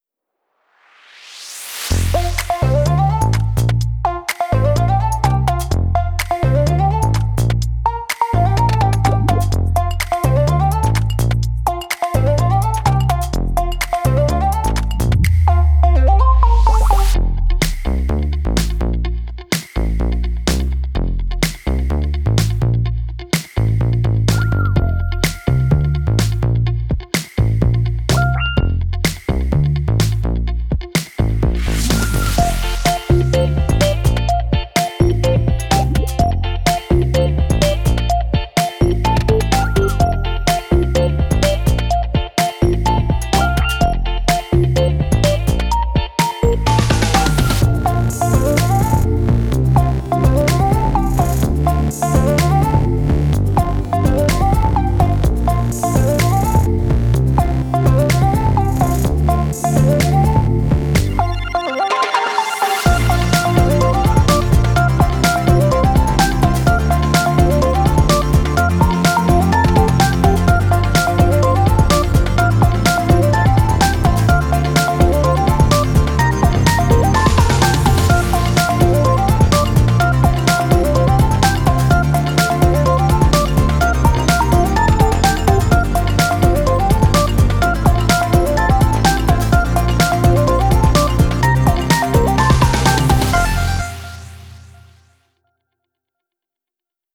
1_LINE_220518_BGM%20OOZ%20WEB1_MASTER.wav